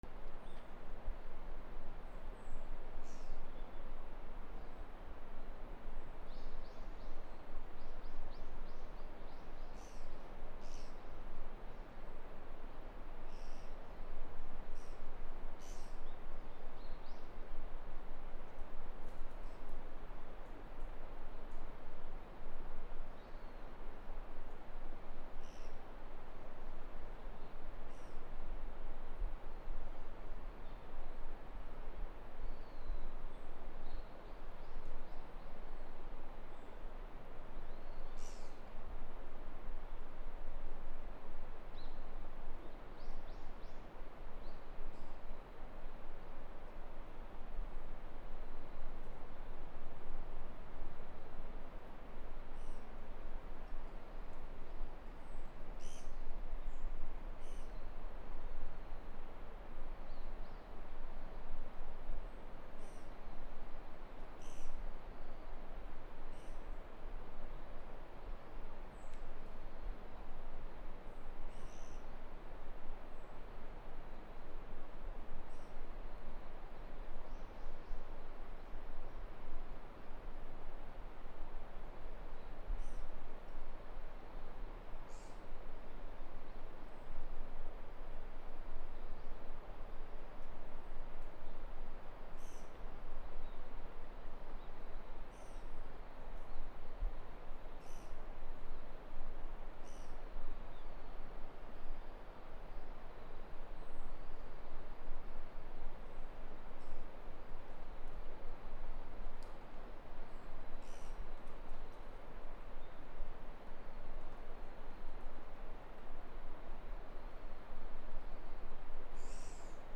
鳥 ギー 約13m 沢近くにあり
NT4 高野山